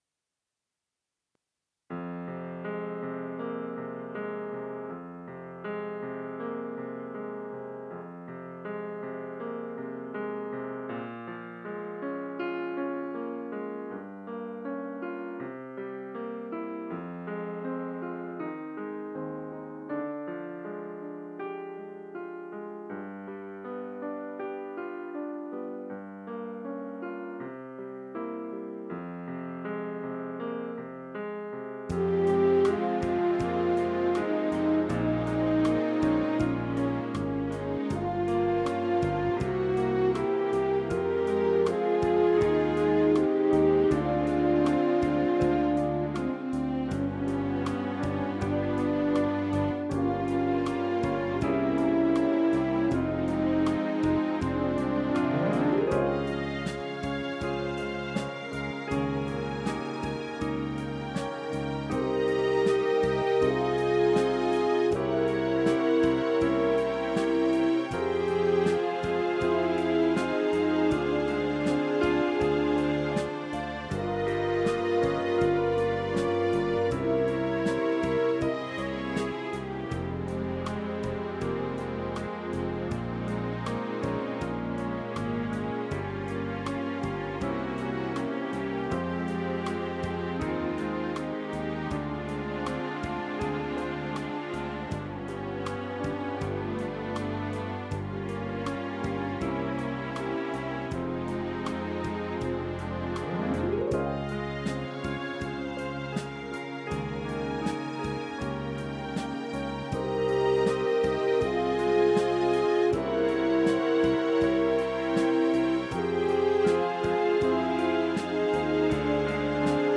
￥29,180（税込）の品　Kaerntner トランペット KTR(ブラック)
★この楽器の私のデモ演奏はこちら★